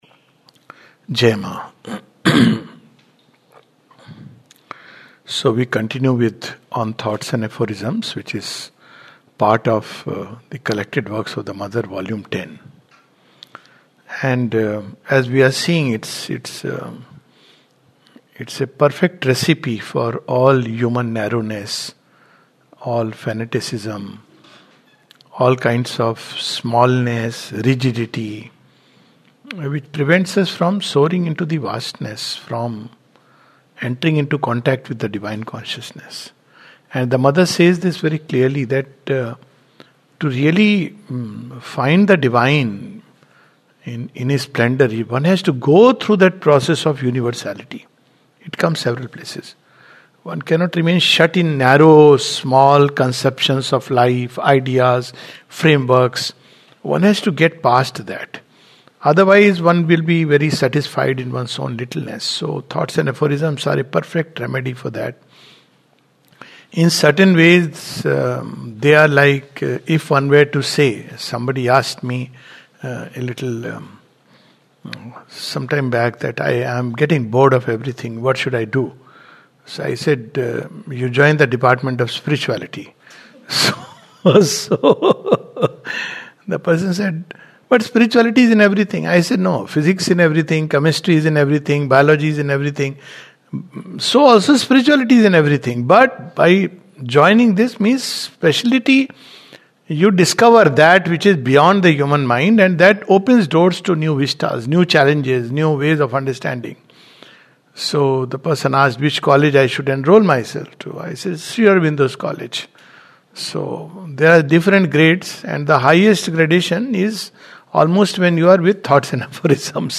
This is the fourth class on CWM 10 On Thoughts and Aphorisms. The subjects touched upon are the Divine Action, resistance of earth nature, the Superman etc.